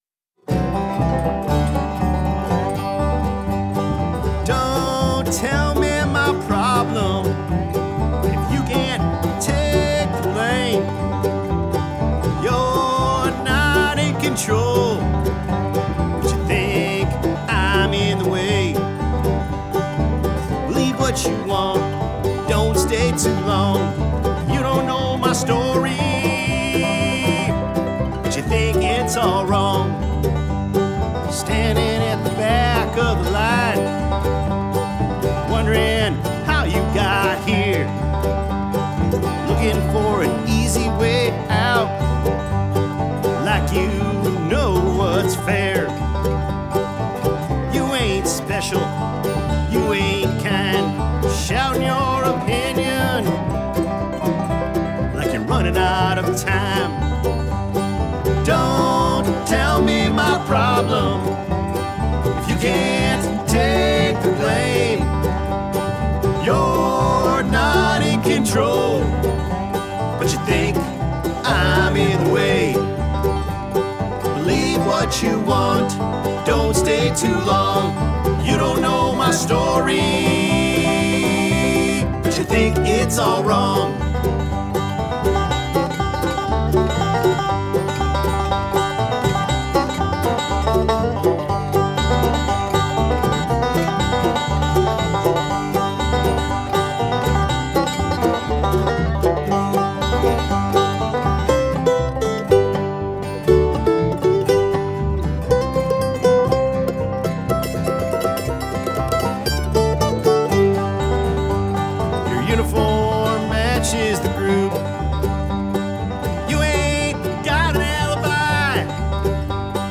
Genre: Acoustic.